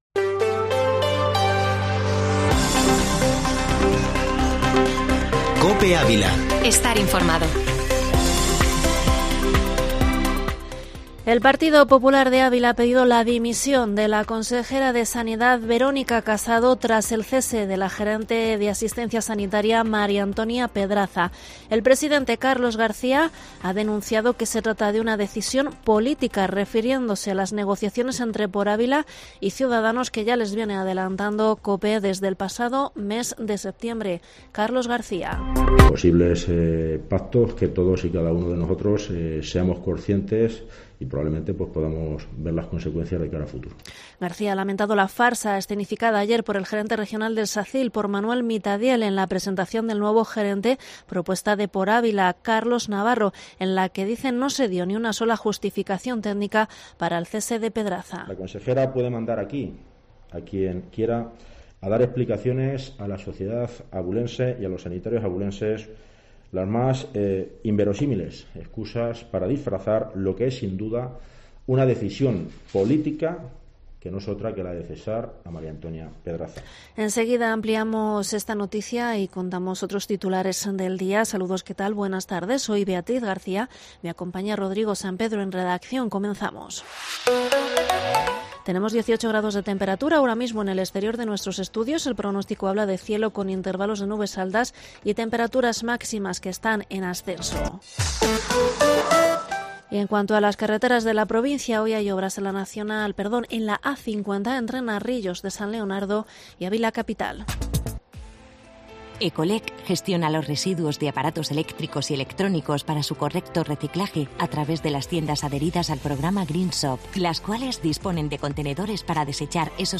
informativo Mediodía COPE ÁVILA 19/10/2021